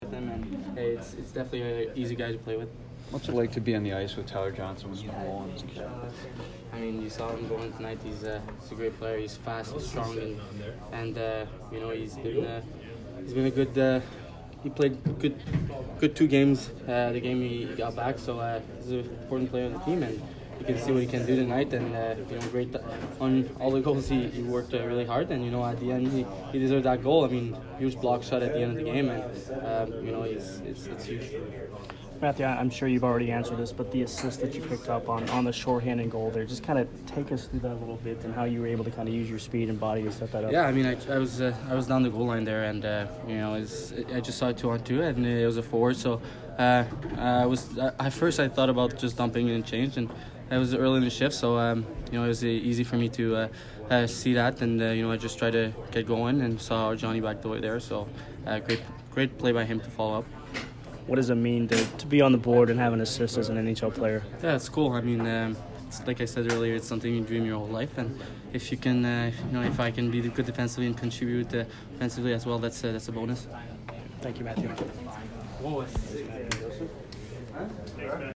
Mathieu Joseph post-game 10/16